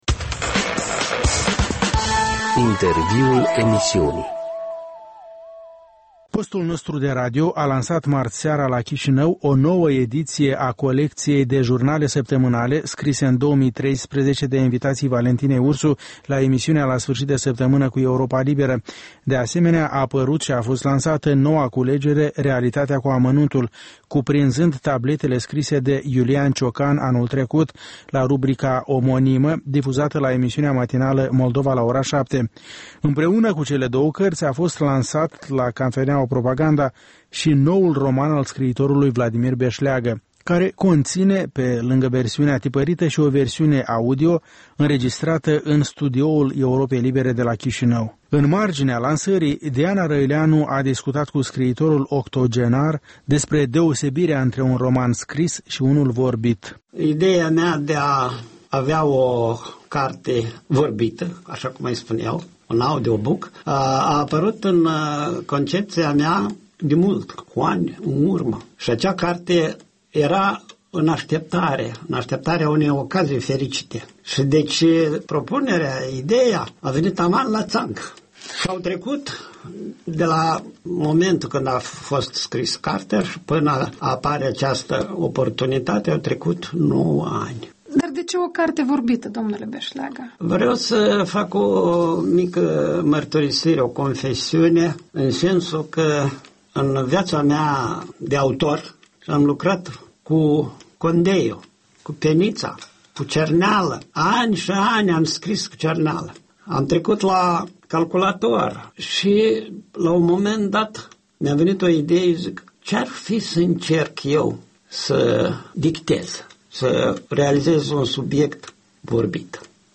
Un interviu despre puterea cărții și audiobook-uri.
Despre cartea scrisă și vorbirea vie cu scriitorul Vladimir Beșleagă